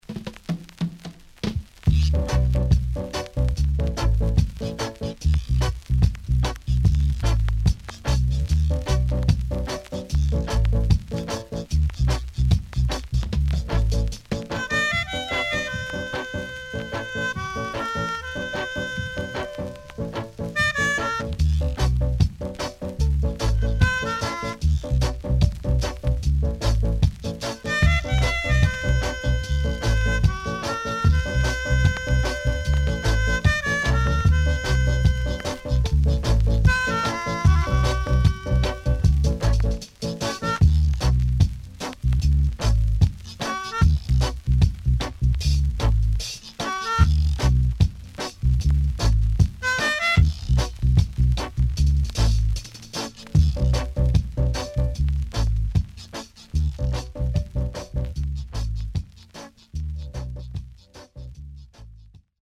SIDE A:ヒスノイズあり。所々チリノイズ、プチノイズ入ります。